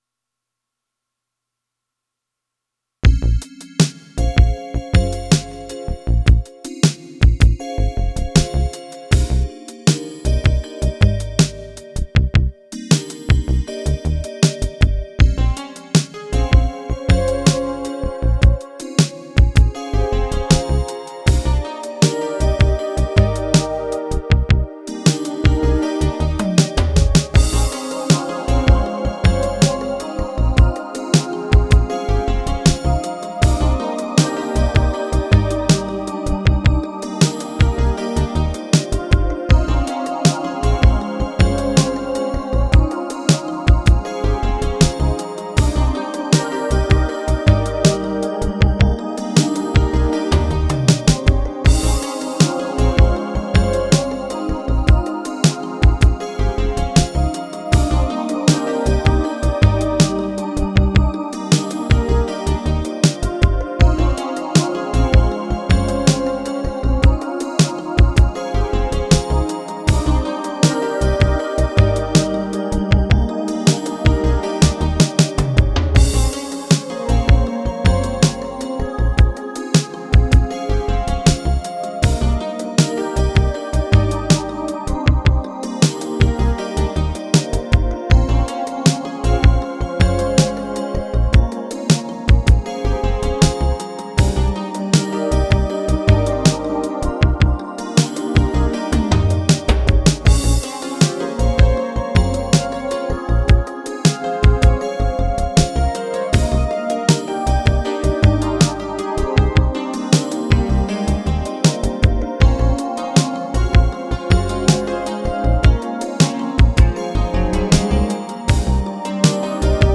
チルアウトを意識している。